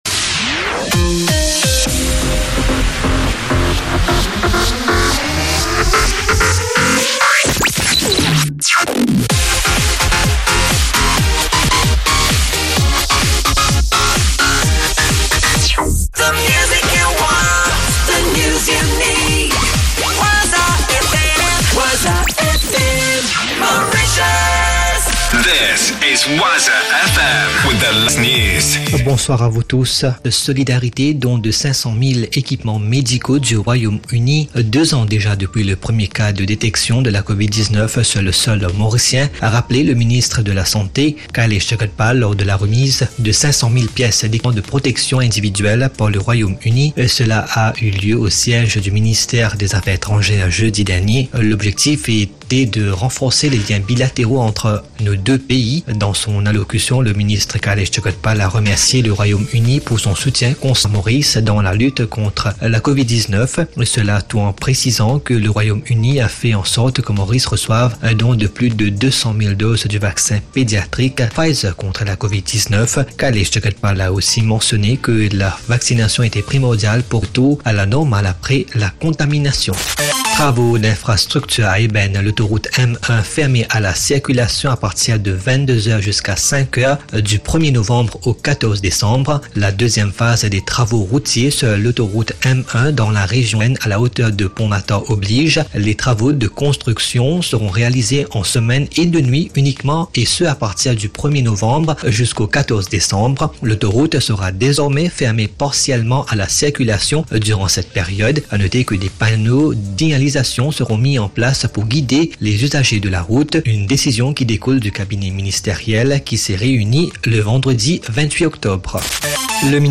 NEWS 29.10.22 20HR